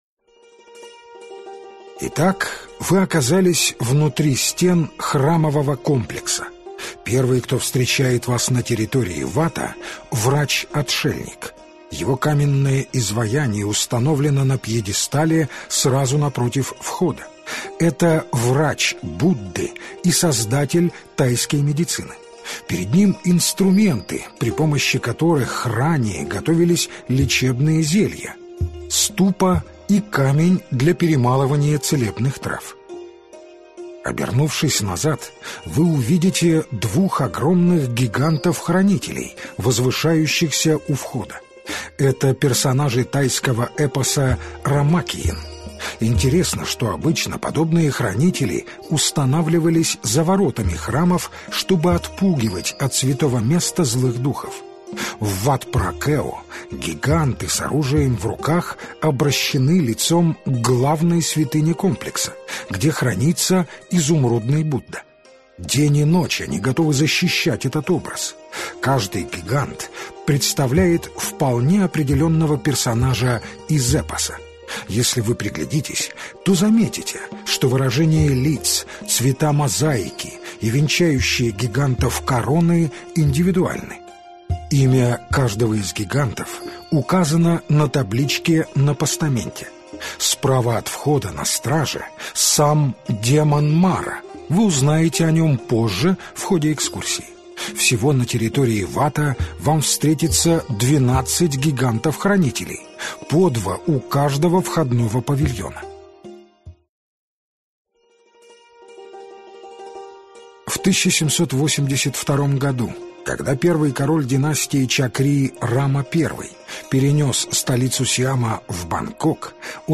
Аудиокнига Бангкок-Паттайя | Библиотека аудиокниг